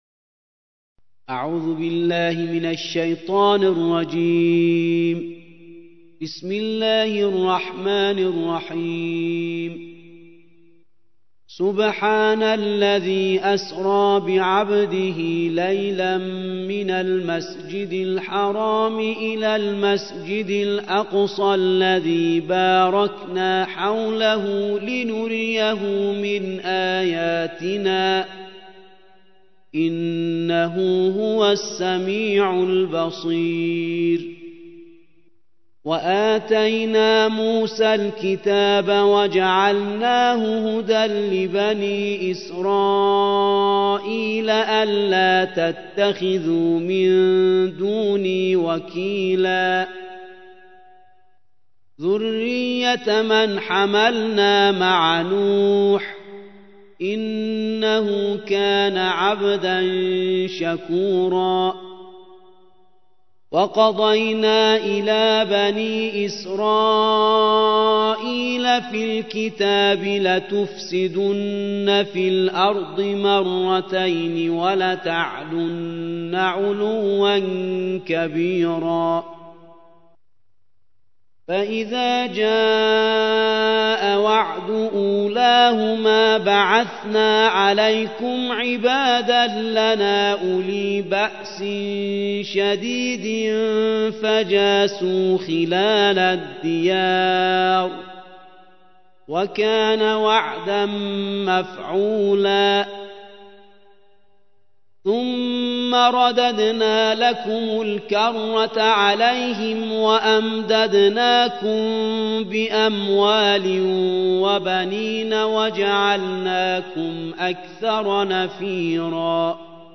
الجزء الخامس عشر / القارئ